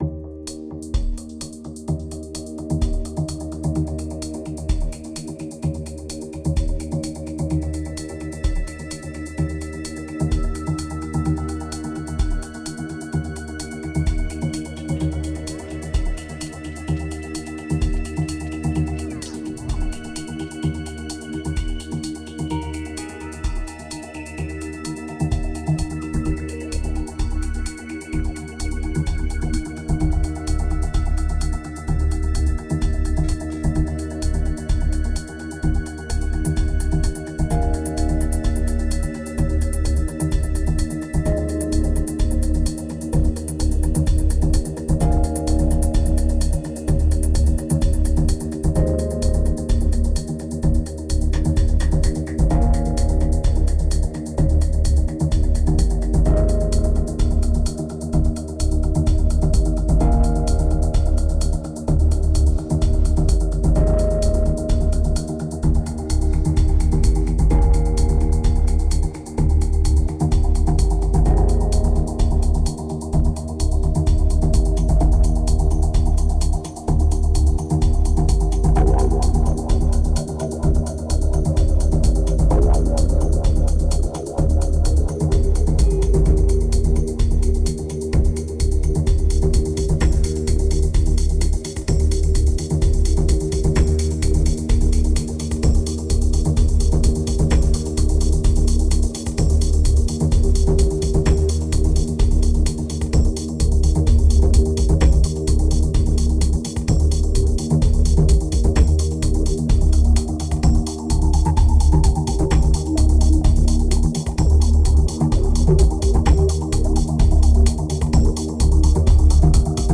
2508📈 - 35%🤔 - 128BPM🔊 - 2012-05-06📅 - -38🌟